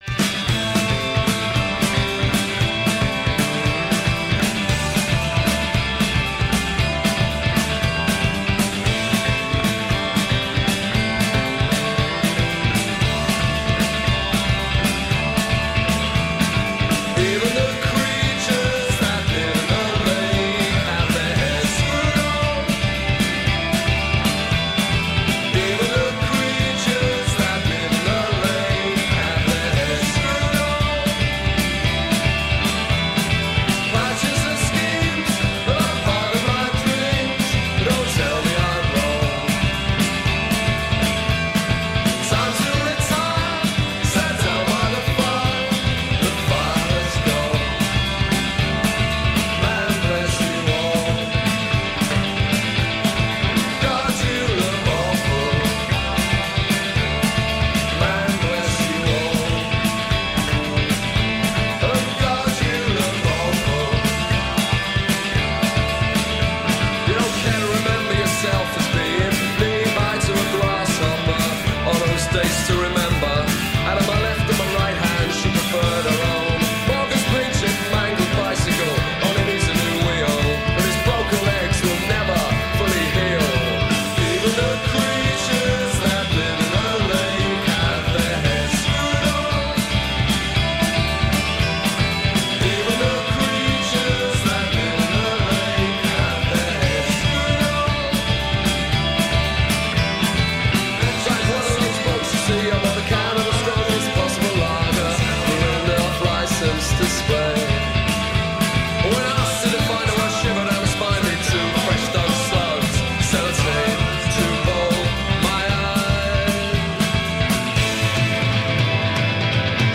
Alternative band